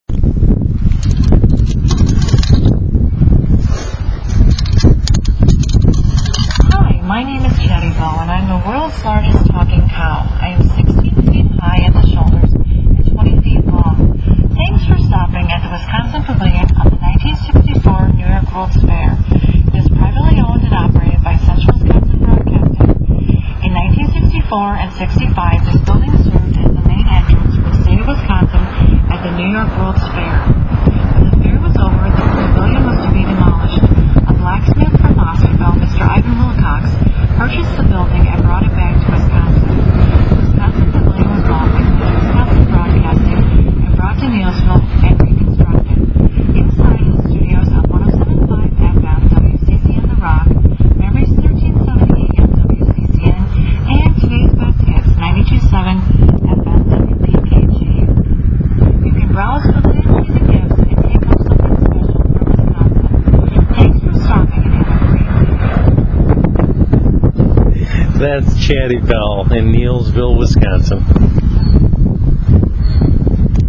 The world's largest talking cow.
Chatty Belle speaks!